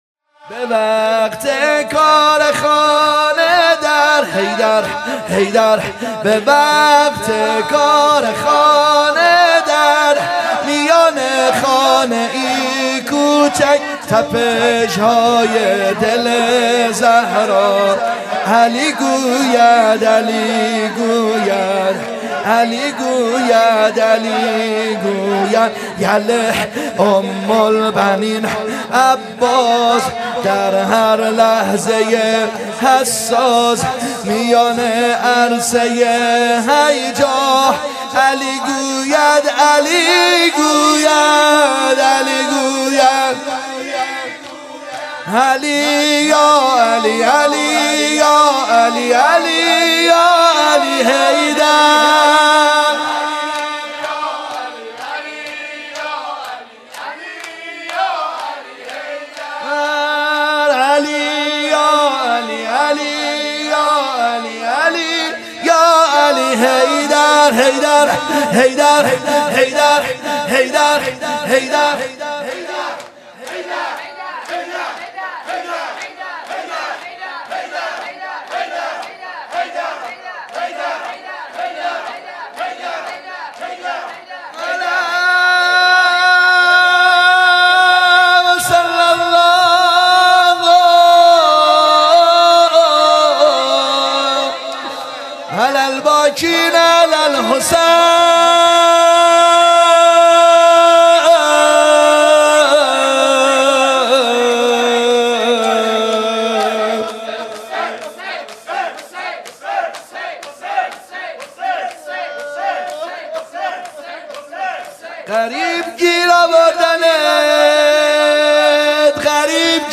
شب بیست و سوم ماه مبارک رمضان سومین شب قدر
? شور